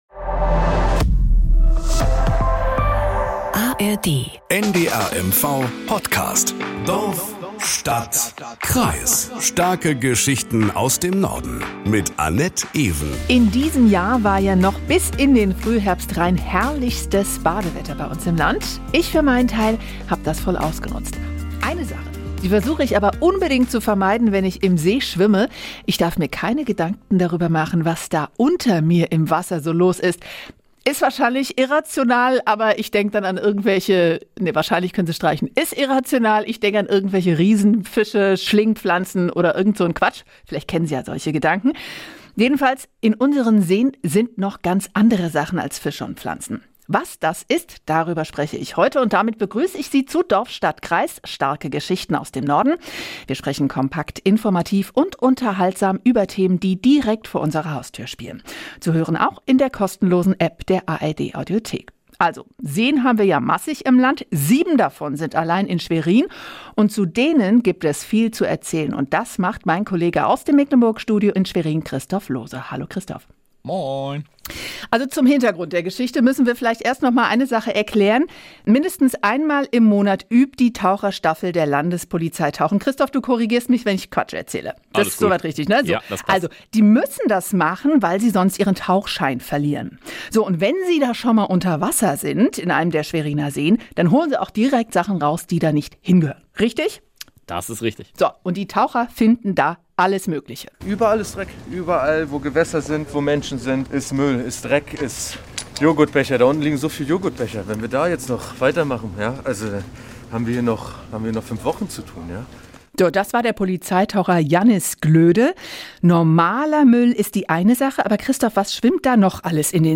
Nachrichten aus Mecklenburg-Vorpommern - 16.01.2024